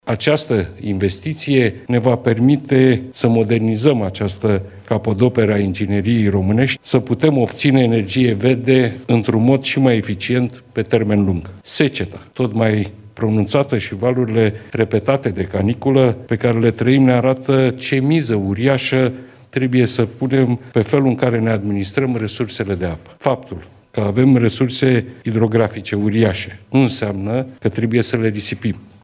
O investiție de 200 de milioane de euro care va ajuta România să obțină și mai multă energie verde pe termen lung. Șeful Guvernului în timpul discursului ținut la Vidraru.